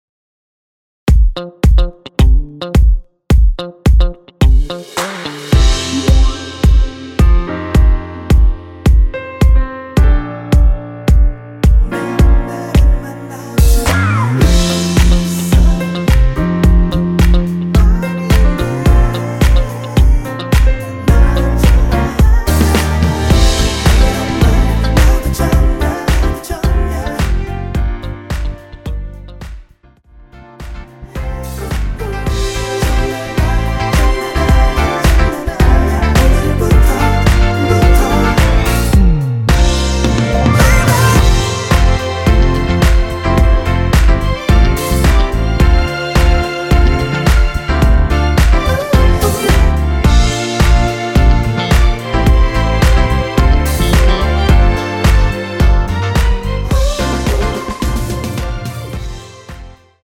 원키에서(+1)올린 코러스 포함된 MR입니다.(미리듣기 확인)
◈ 곡명 옆 (-1)은 반음 내림, (+1)은 반음 올림 입니다.
앞부분30초, 뒷부분30초씩 편집해서 올려 드리고 있습니다.